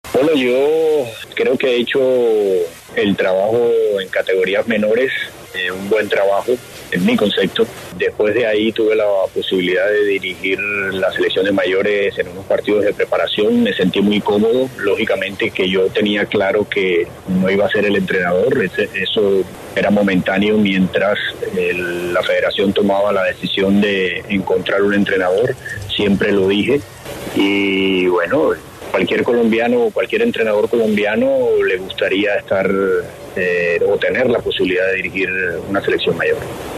DT colombiano, en diálogo con El Alargue